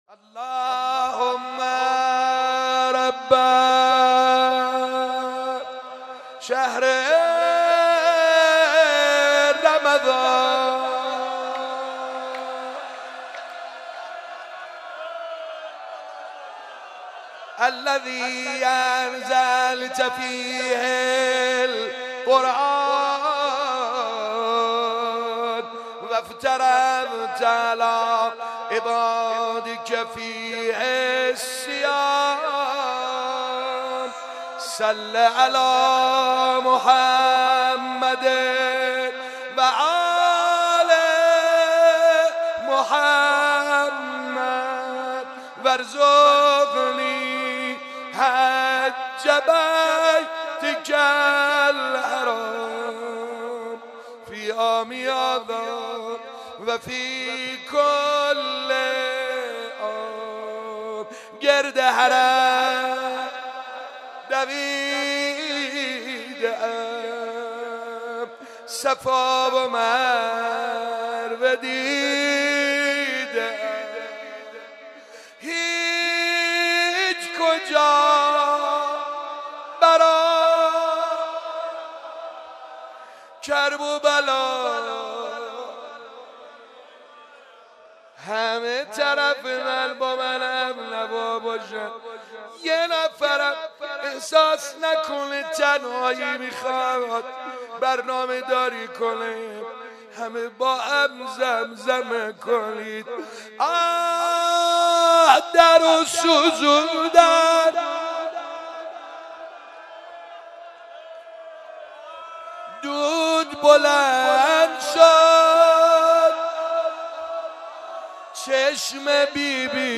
مناجات با خداوند و روضه خوانی